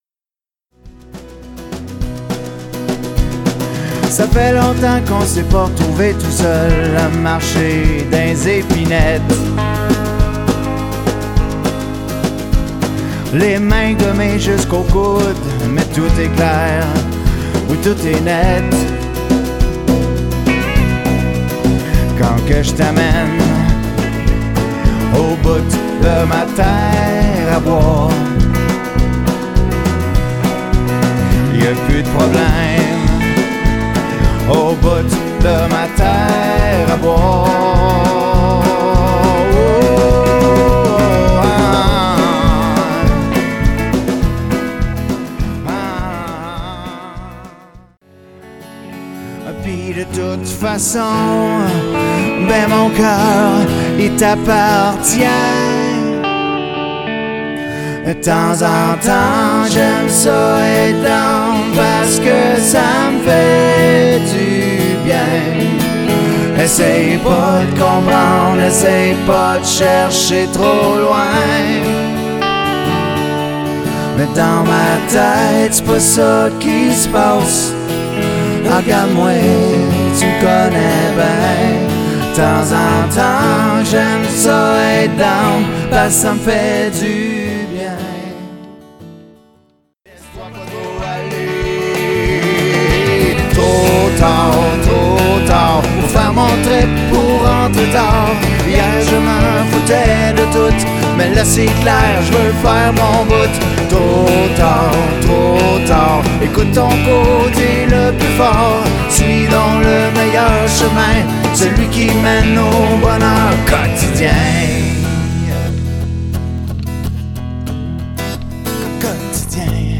Voici un medley des chansons de l'album